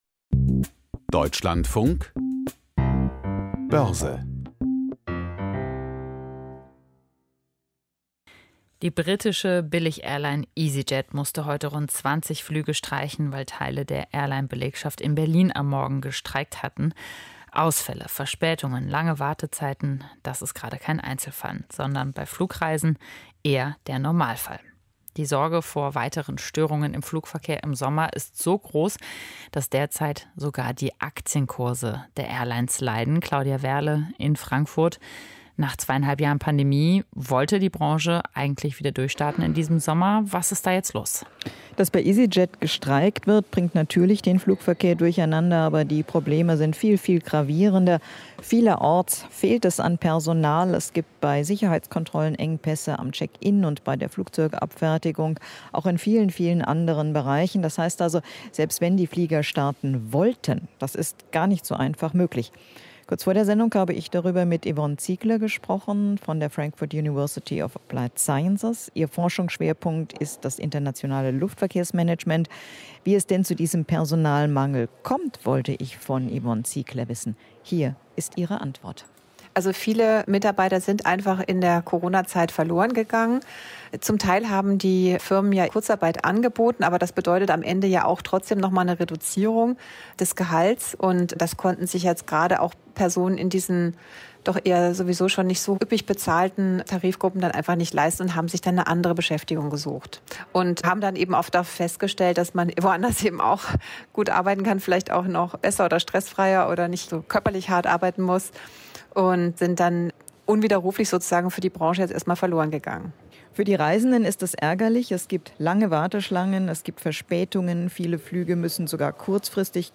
Börsengespräch aus Frankfurt